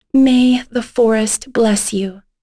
Lorraine-Vox_Victory_b.wav